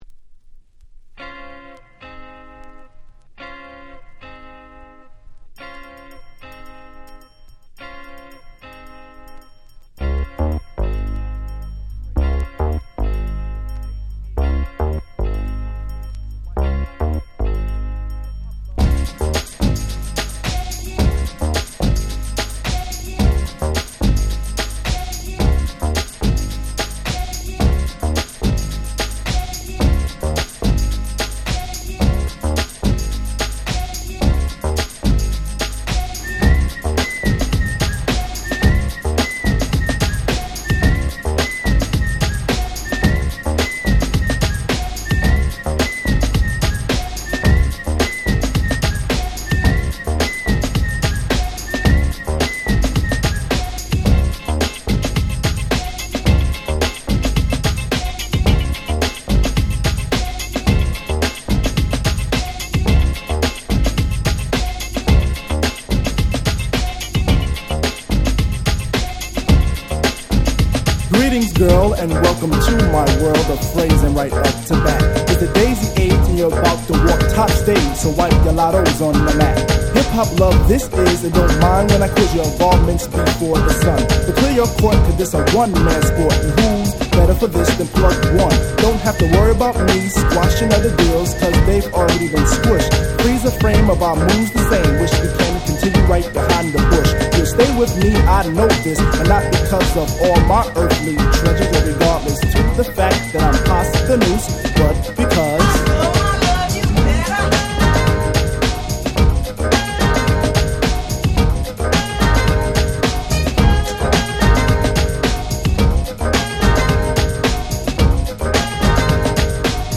89' Smash Hit Hip Hop !!